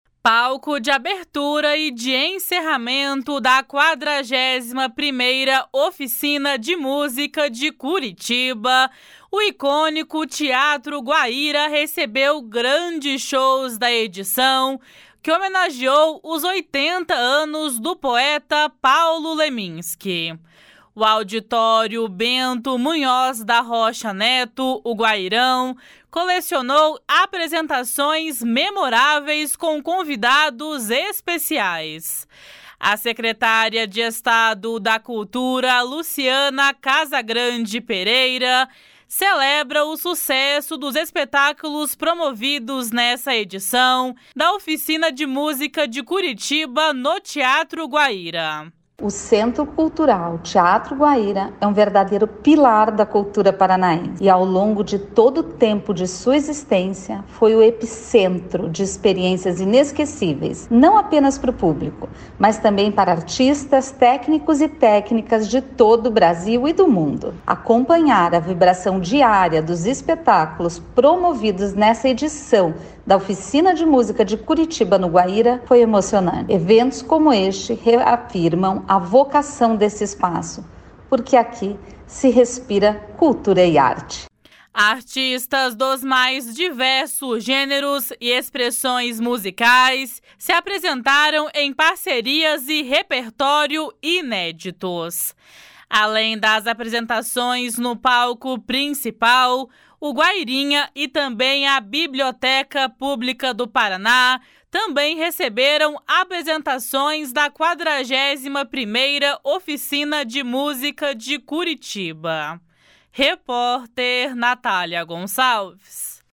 A secretária de Estado da Cultura, Luciana Casagrande Pereira, celebra o sucesso dos espetáculos promovidos nesta edição da Oficina de Música de Curitiba no Teatro Guaíra. // SONORA LUCIANA CASAGRANDE PEREIRA //